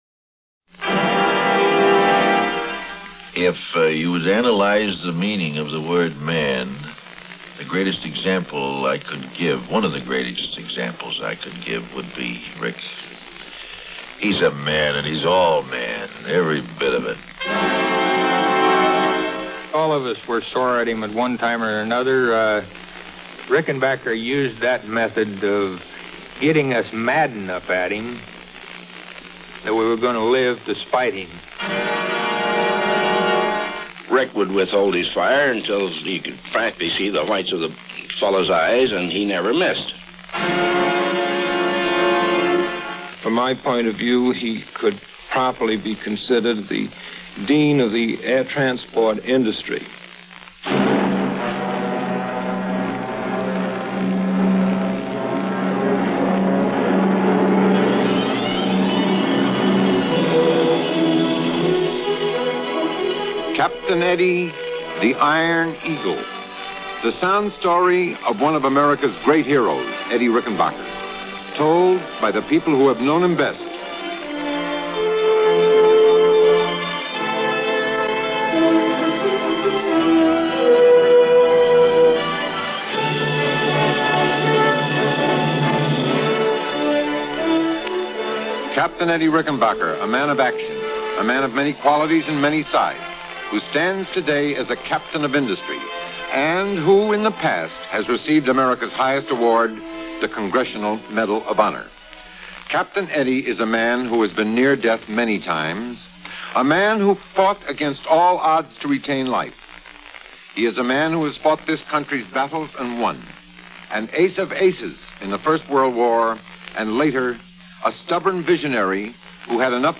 Walter O'Keefe narrator